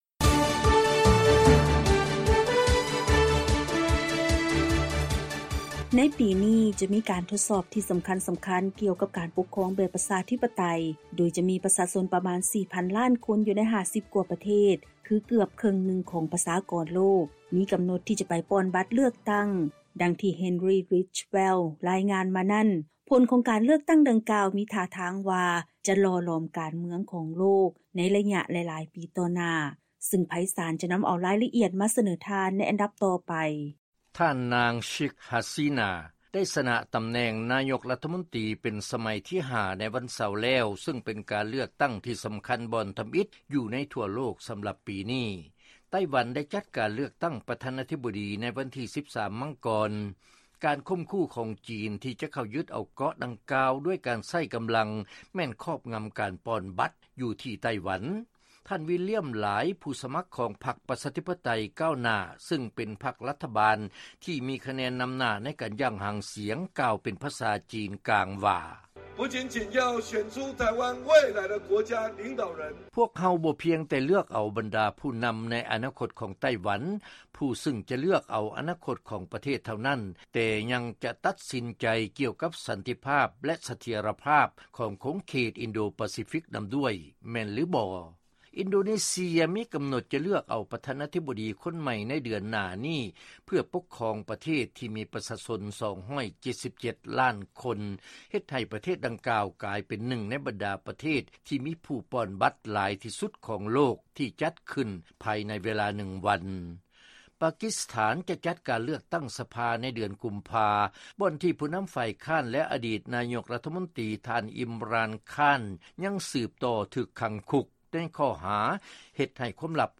ເຊີນຟັງລາຍງານ ປະຊາຊົນເຄິ່ງນຶ່ງຂອງໂລກ ຈະພາກັນໄປປ່ອນບັດເລືອກຕັ້ງແຫ່ງຊາດ ໃນປີ 2024